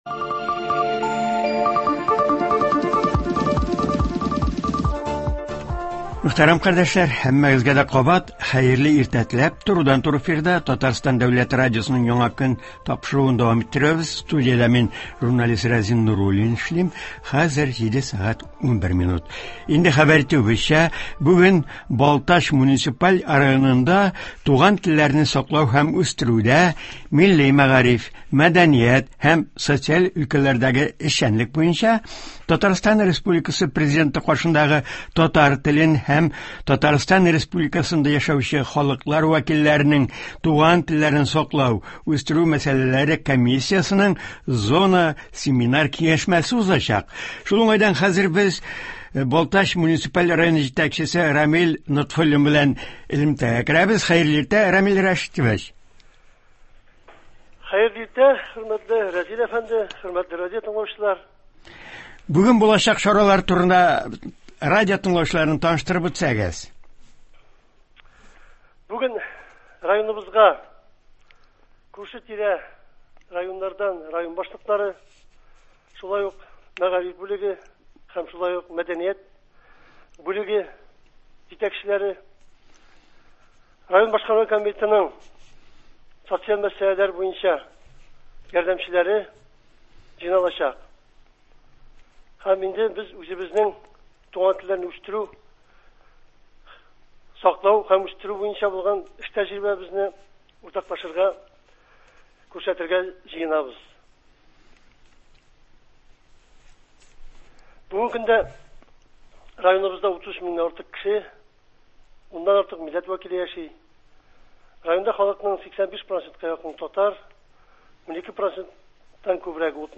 Буген Балтач муниципаль районында Татарстан Президенты каршындагы Татар телен, Татарстанда яшәуче халыклар вәкилләренең туган телләрен саклау, устеру мәсьәләләре комиссиясенең зона семинар-киңәшмәсе була. Район җитәкчесе Рамил Нотфуллин белән телефон элемтәсе шул хакта.